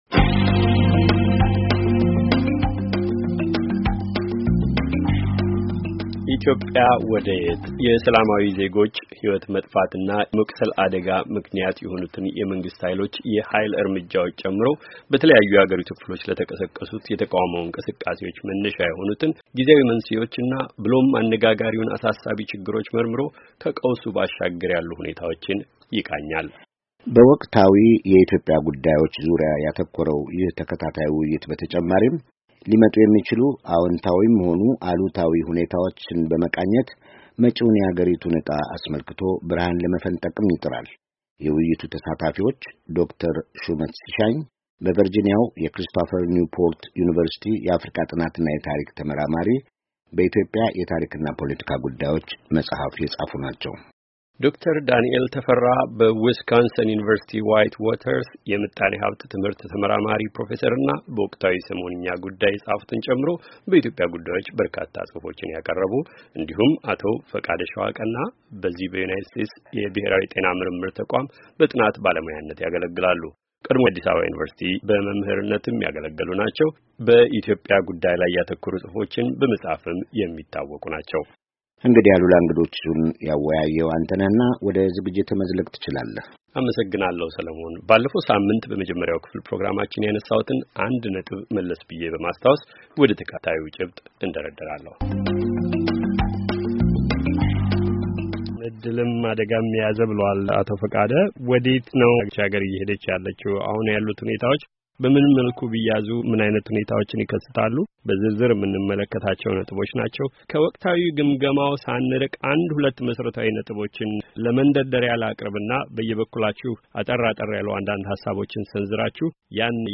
ውይይት:- ኢትዮጵያ ወደ የት?